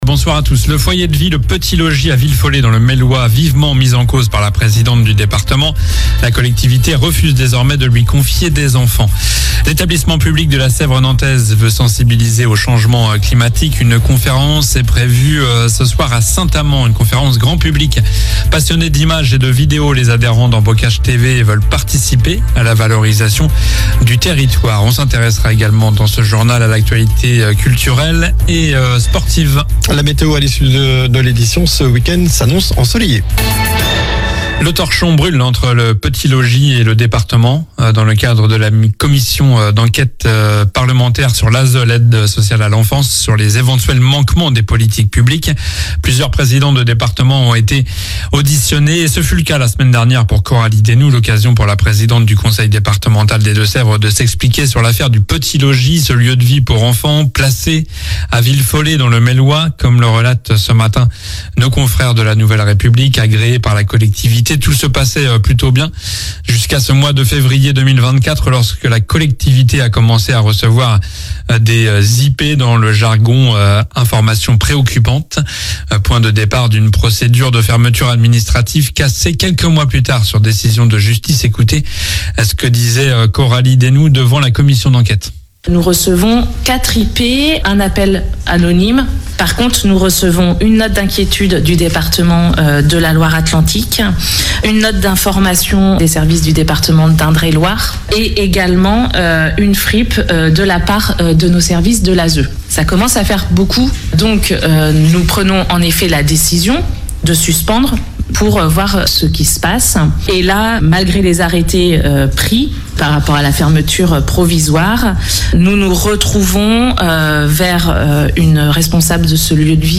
Journal du vendredi 31 janvier (soir)